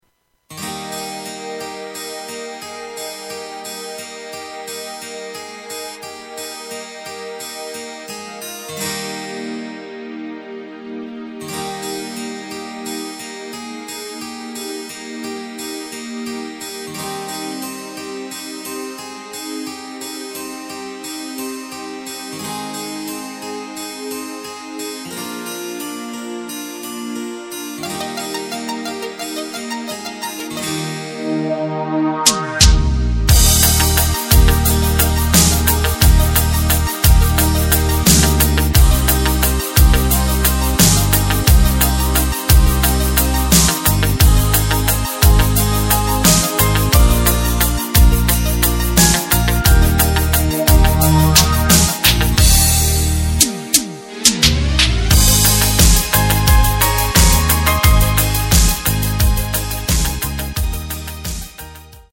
Takt:          4/4
Tempo:         88.00
Tonart:            Eb
Schlager aus dem Jahr 2018!
Playback Demo mit Lyrics